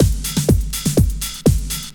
OSH Rider Beat 1_123.wav